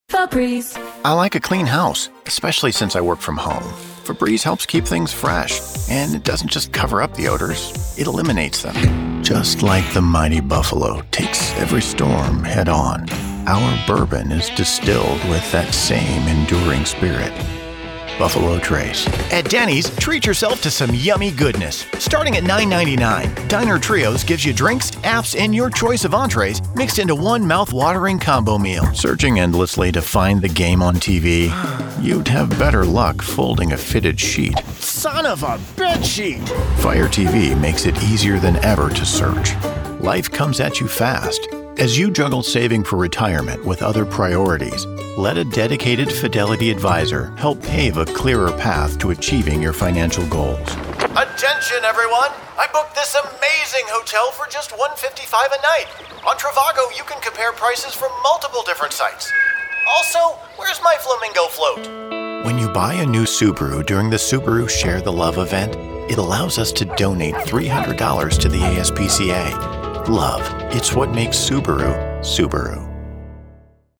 Slightly irreverent, effortlessly brilliant Voiceover.
Commercial Demo
He has also been described as having a great commercial sound (light and conversational), and sharp instincts for copy.